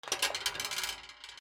ギミック 金属
『カチャシュイイ』